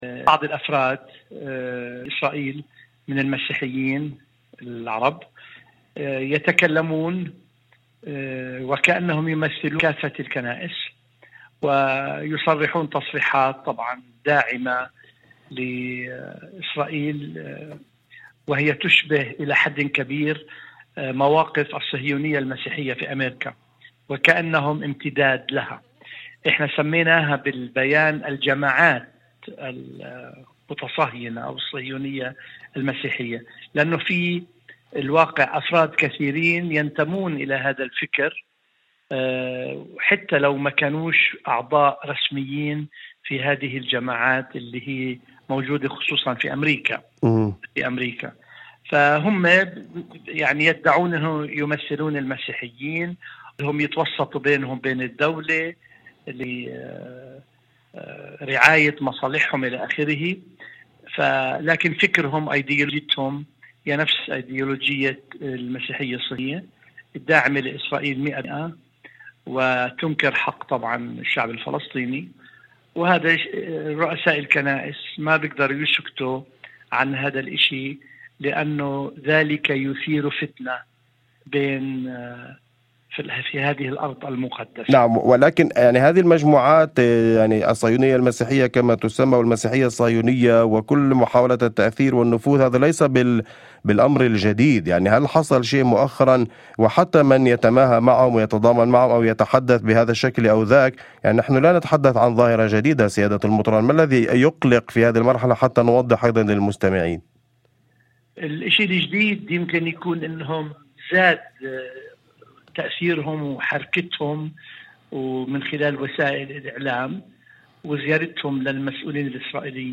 وأضاف في مداخلة هاتفية لبرنامج "أول خبر"، على إذاعة الشمس: